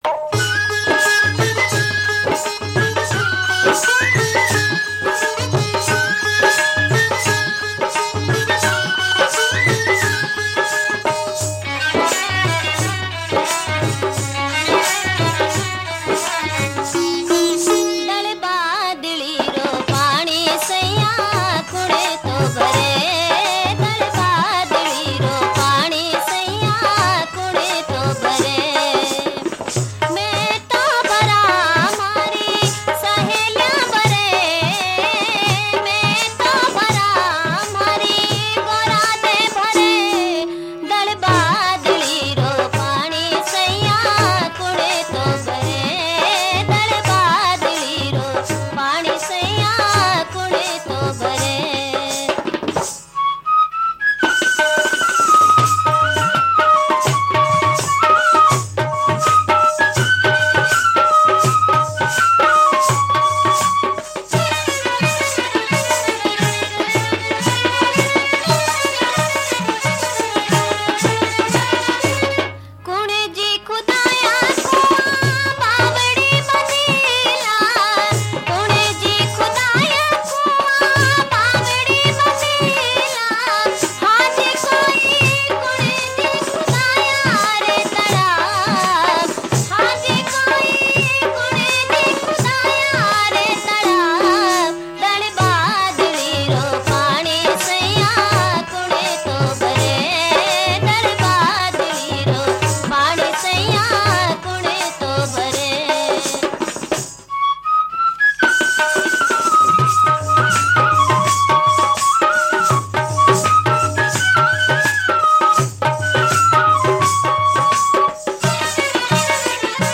Rajasthani Songs
(Live)